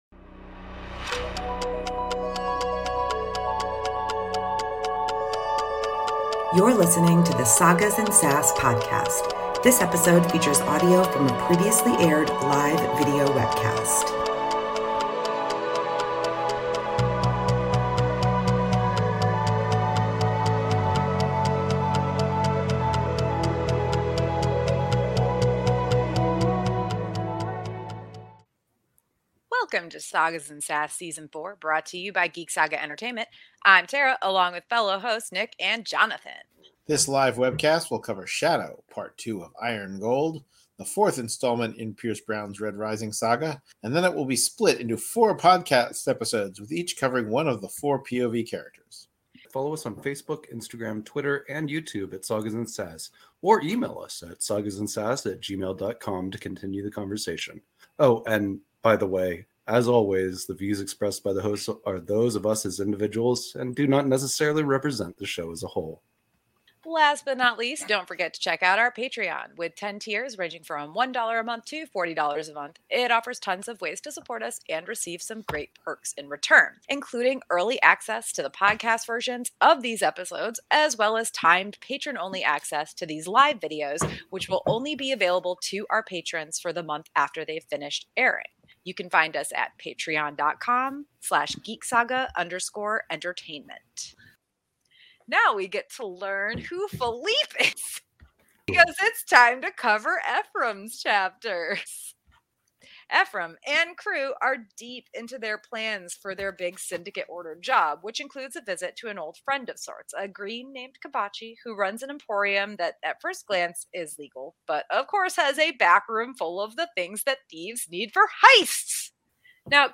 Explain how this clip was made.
Episode 64 of the Sagas & Sass Podcast originally aired as a live webcast on March 22, 2023.